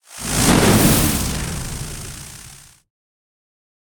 spell-impact-lightning-2.ogg